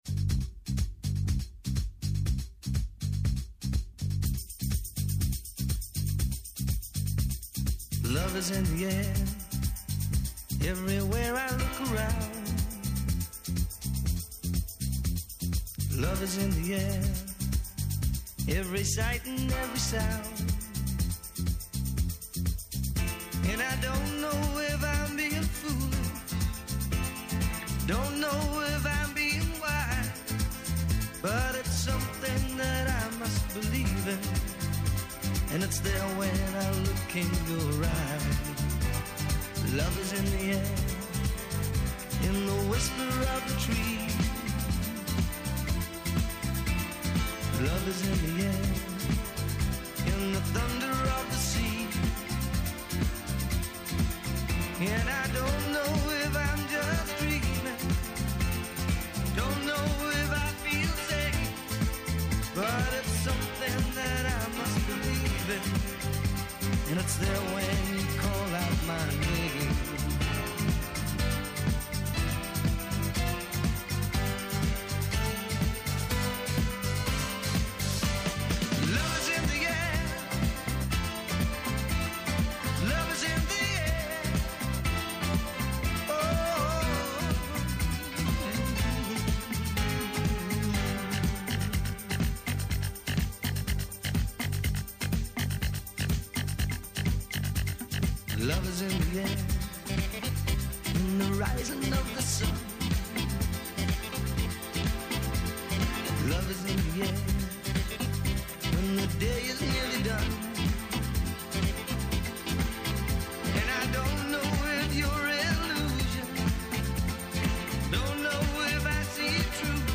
Καλεσμένοι τηλεφωνικά σήμερα :